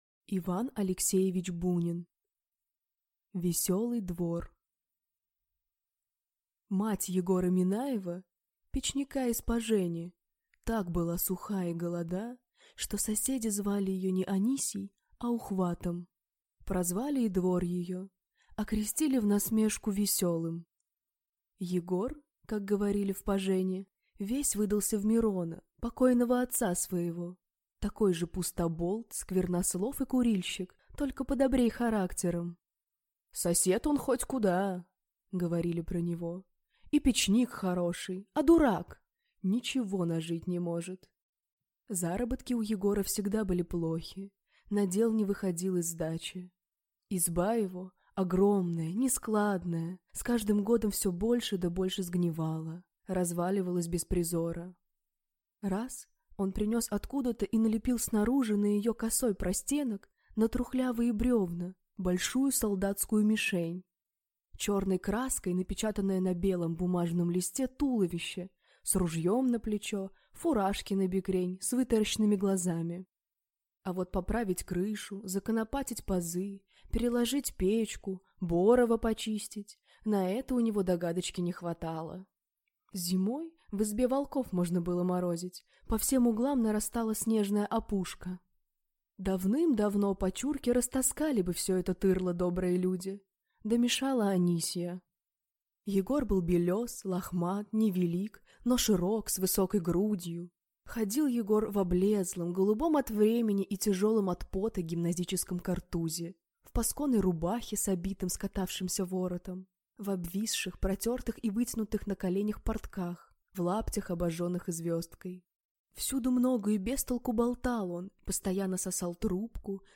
Аудиокнига Веселый двор | Библиотека аудиокниг
Прослушать и бесплатно скачать фрагмент аудиокниги